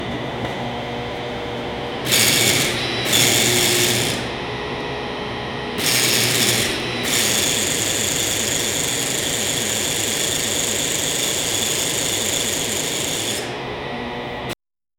exercise-bike-in-a-gym-p2ydvyx2.wav